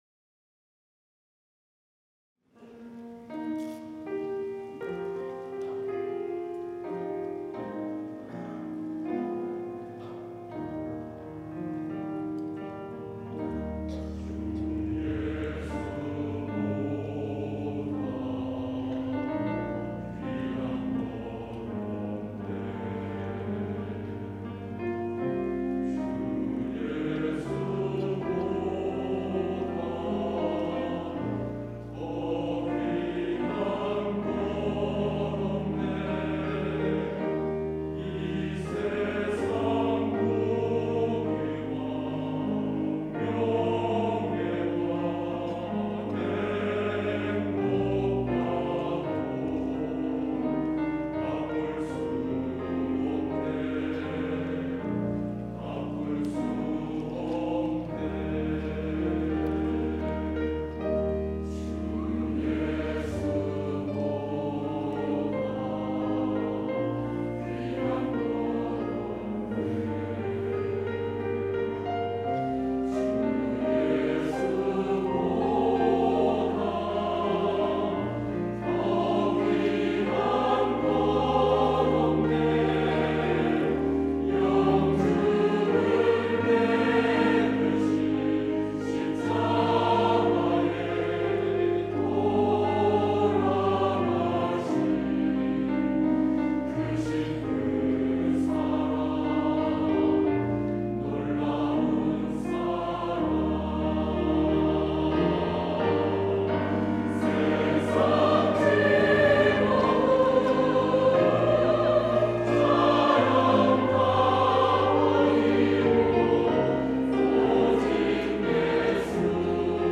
할렐루야(주일2부) - 주 예수보다 더 귀한 것은 없네
찬양대